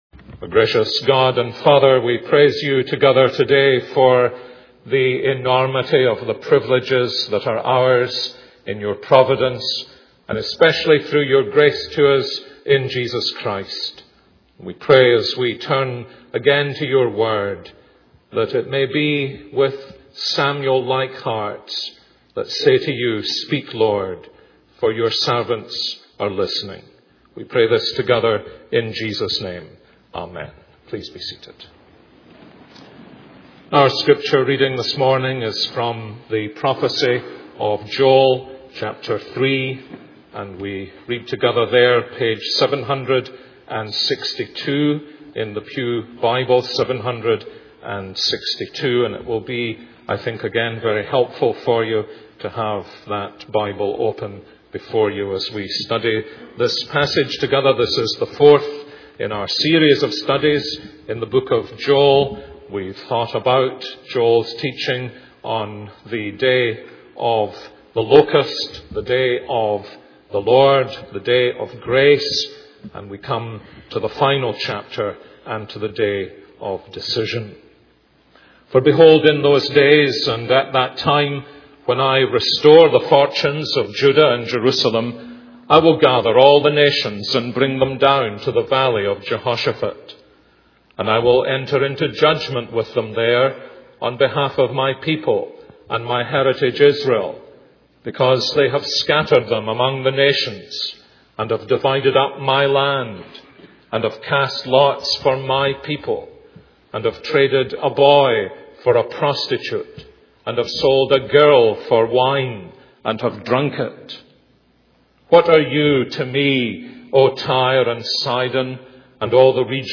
This is a sermon on Joel 3:1-21.